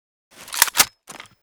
9a91_unjam.ogg